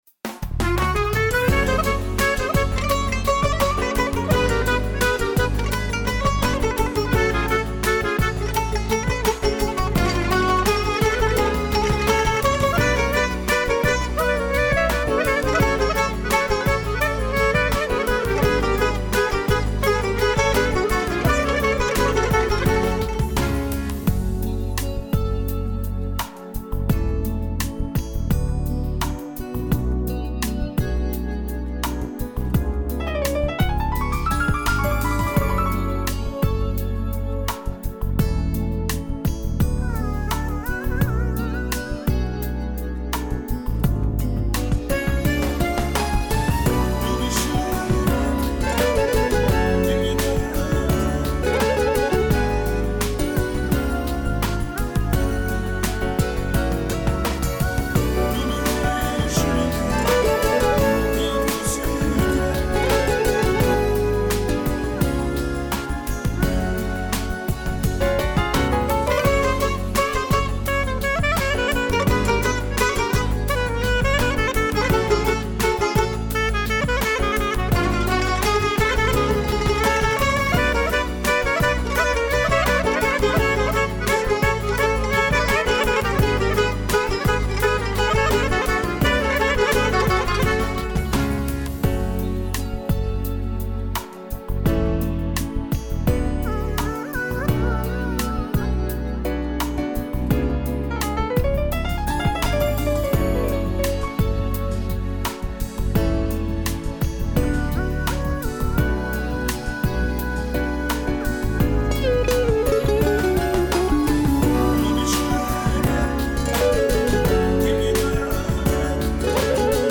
минусовка версия 243286